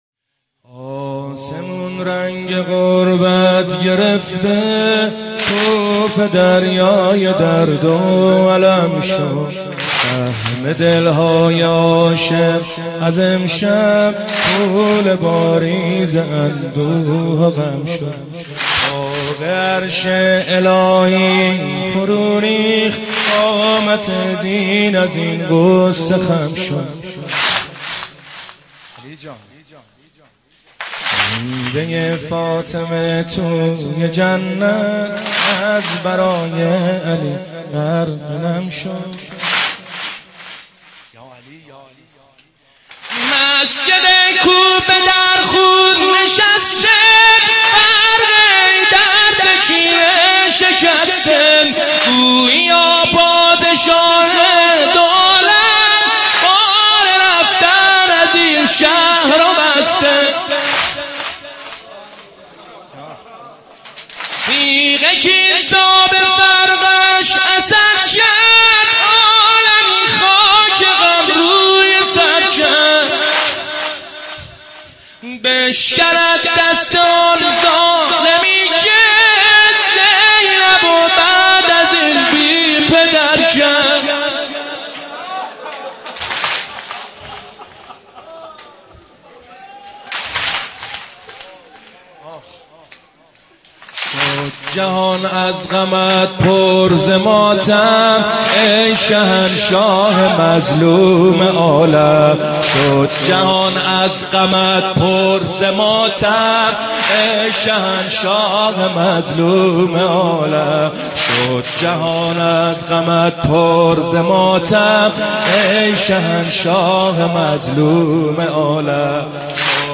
دانلود مرثیه‌سرایی ویژه شب قدر
به مناسبت شب بیست‌وسوم ماه مبارک رمضان بسته صوتی(شنیداری) شامل مجموعه‌ای از مرثیه‌سرایی‌های ماندگار تقدیم مخاطبان گرامی ایکنا می‌شود.